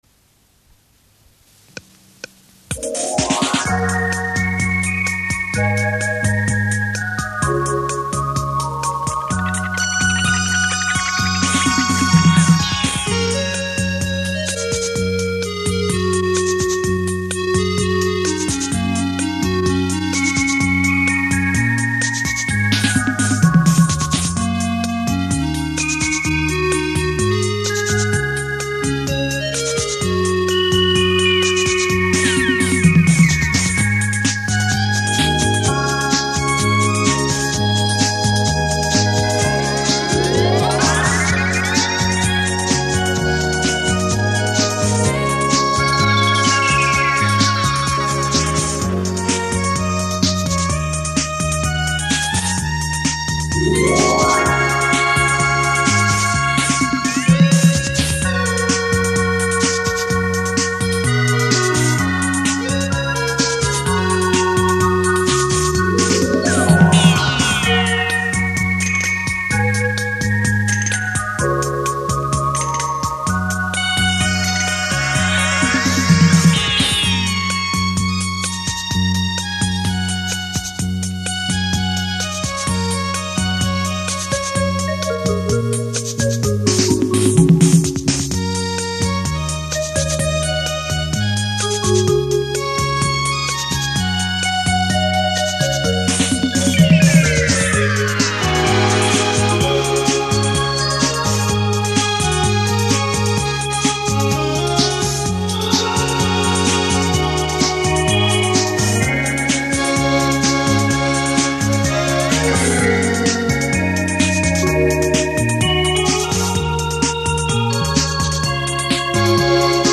音乐介质:磁带转MP3/320K